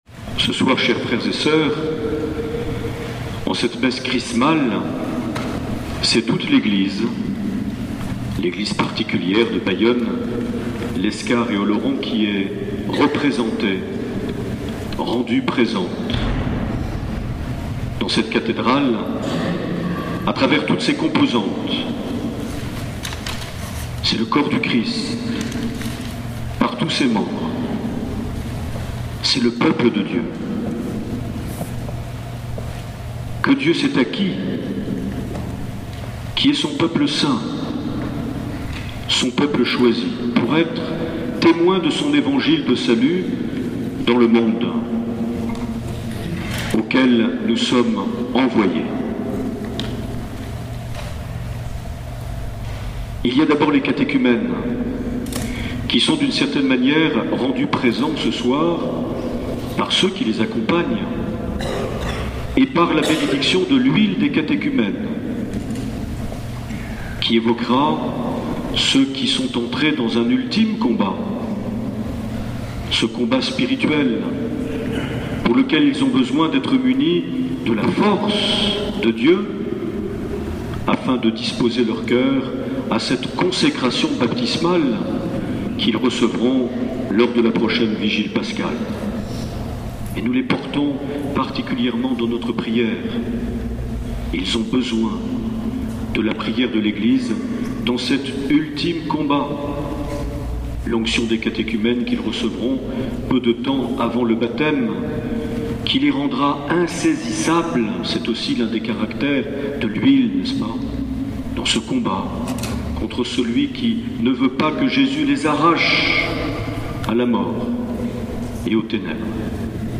2 avril 2012 - Cathédrale de Lescar - Messe Chrismale
Les Homélies
Une émission présentée par Monseigneur Marc Aillet